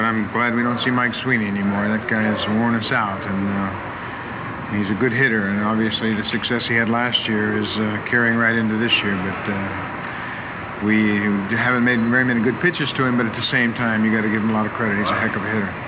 Here you'll find baseball-related sound files, from players talking about the game, to the Baseball Tonight theme song.